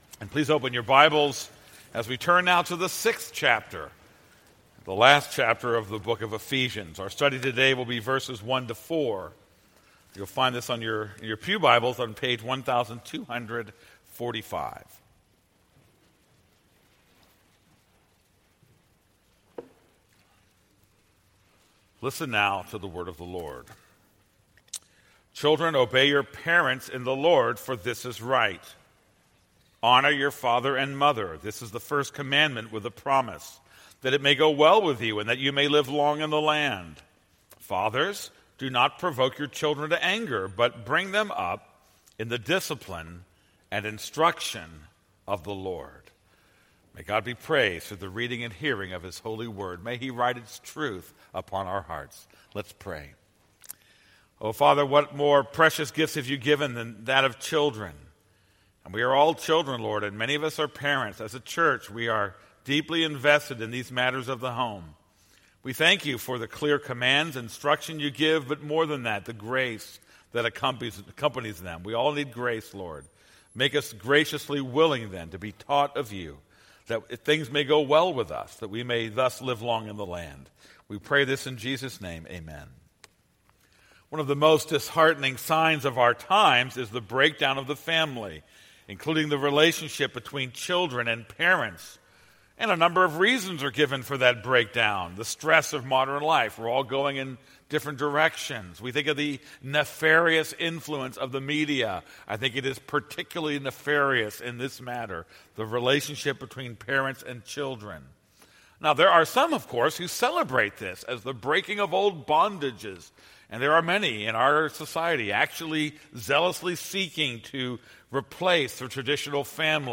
This is a sermon on Ephesians 6:1-4.